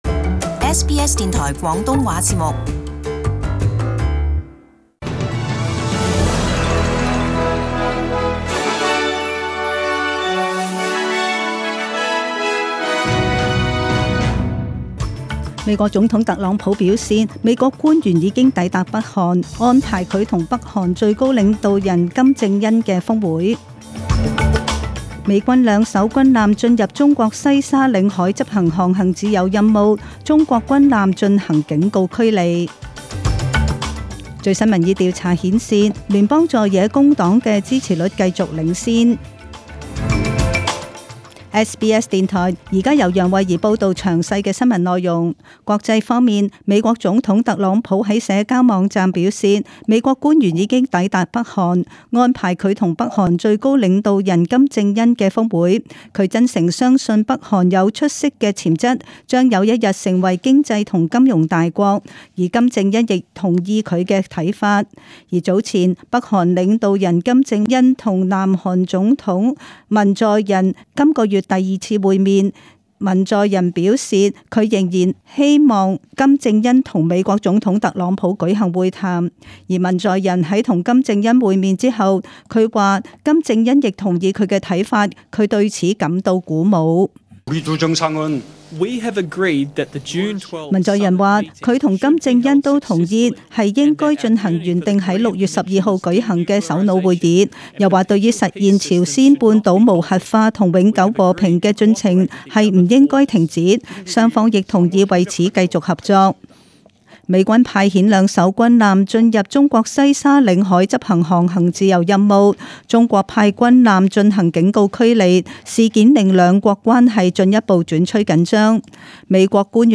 Detailed morning news bulletin.